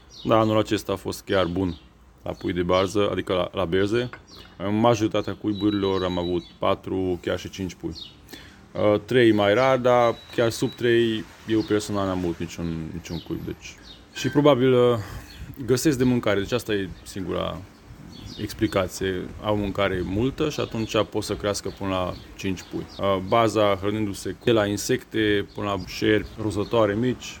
biolog: